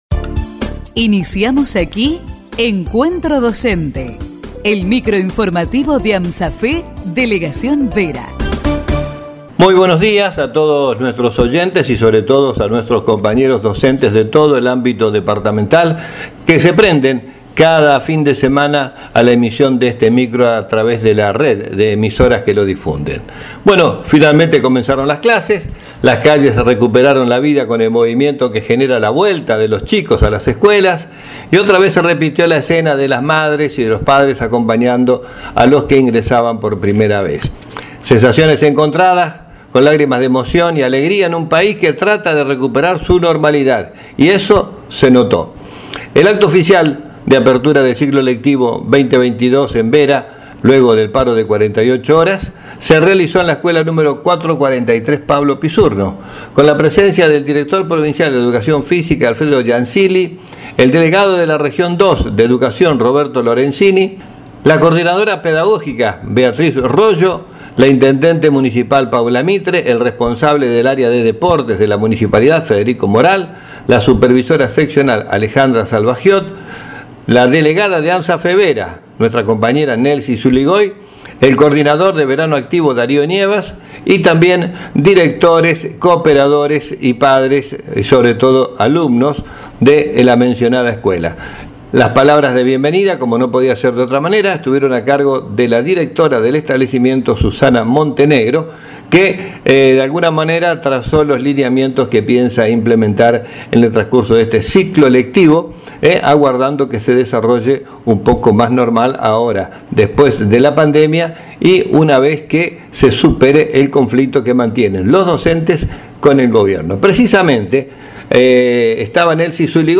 Micro informativo semanal de AMSAFE Vera.